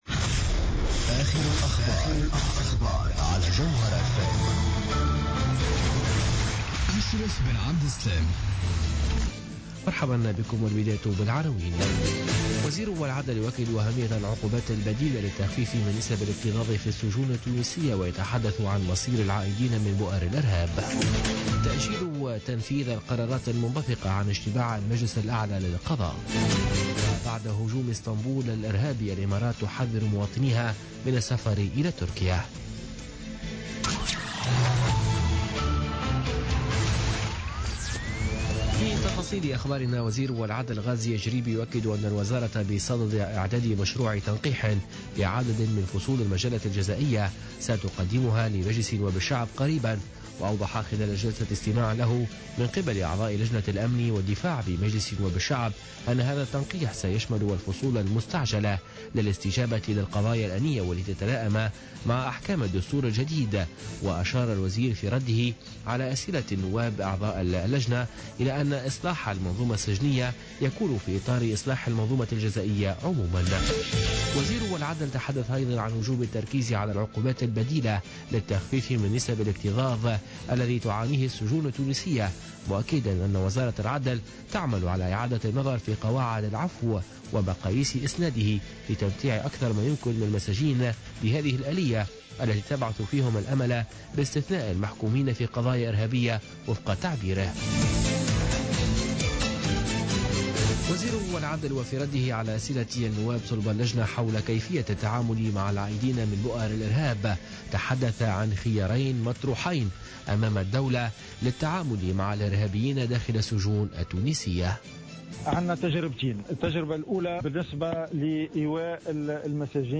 نشرة أخبار منتصف الليل ليوم الثلاثاء 3 جانفي 2017